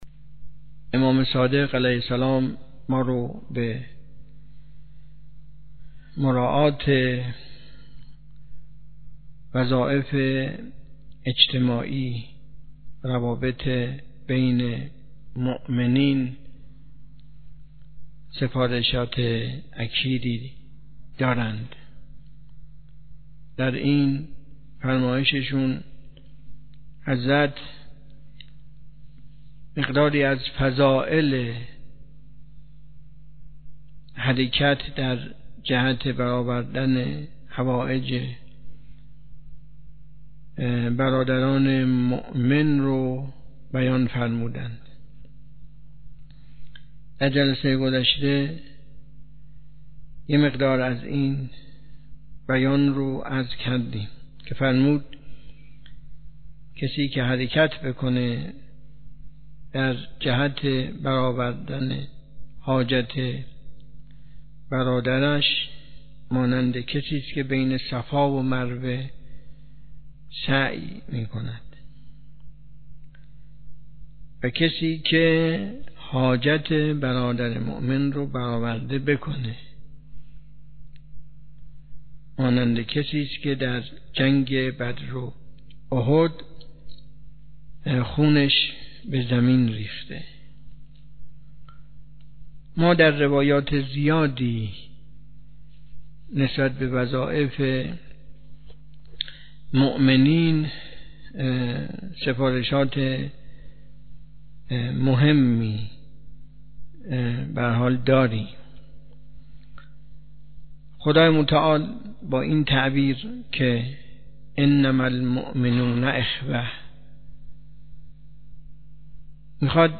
امروز در جلسه درس اخلاق که در مدرسه علمیه معیر برگزار شد